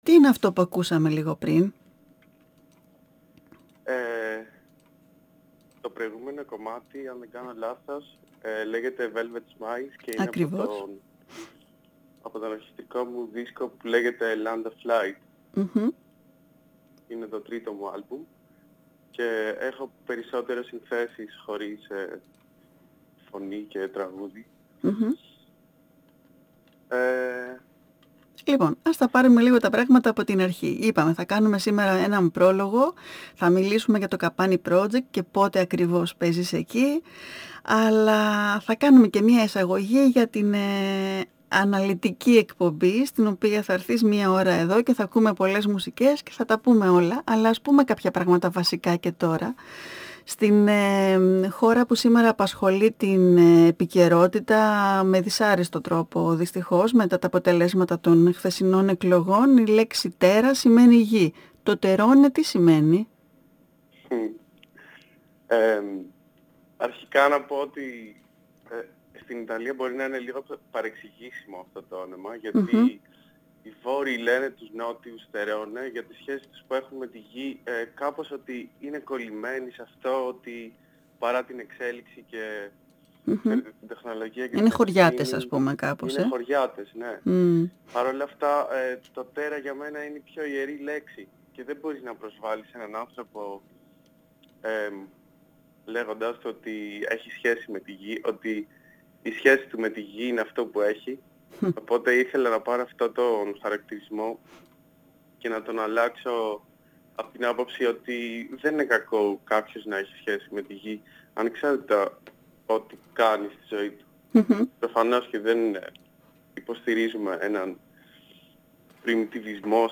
Η συνέντευξη πραγματοποιήθηκε την Πέμπτη 29 Σεπτεμβρίου 2022 στην εκπομπή “Καλημέρα” στον 9,58fm της ΕΡΤ3.